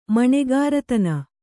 ♪ maṇegāratana